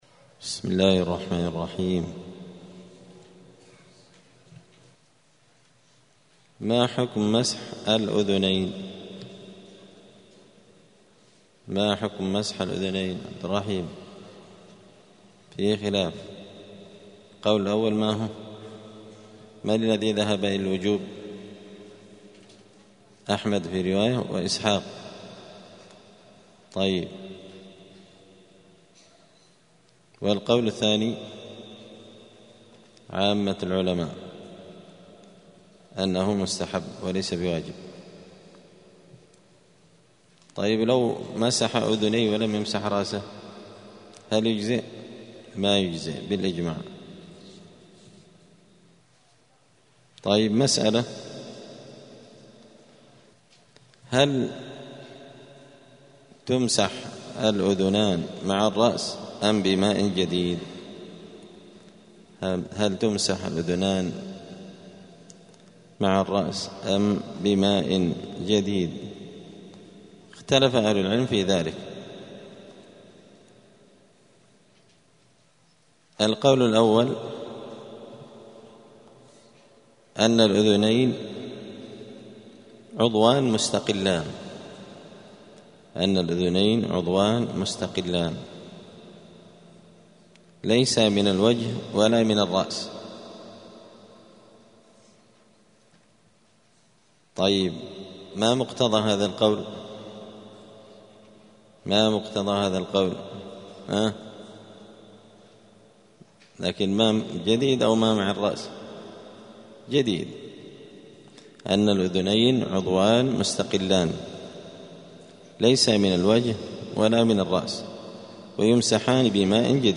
دار الحديث السلفية بمسجد الفرقان قشن المهرة اليمن
*الدرس الثاني والثلاثون [32] {باب صفة الوضوء حكم مسح الرأس والأذنين…}*